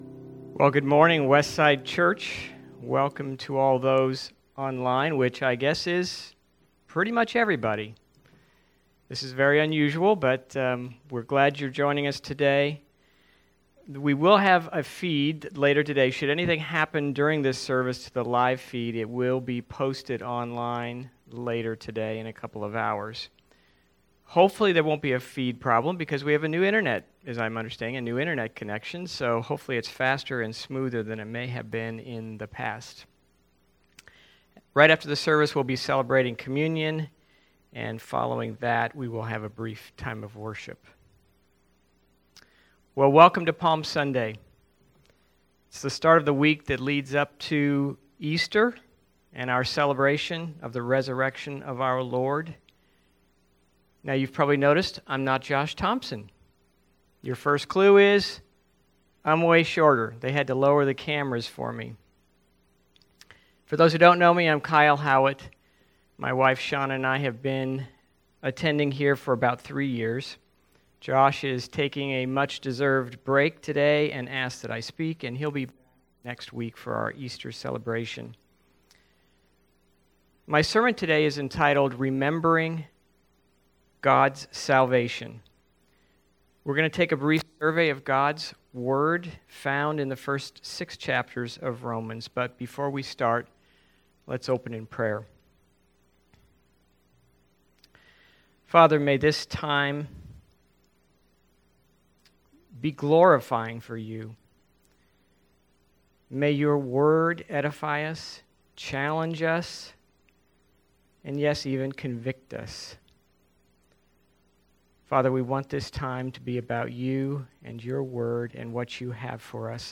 Service Type: Special Sermons